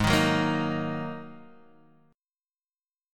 G#7sus2 chord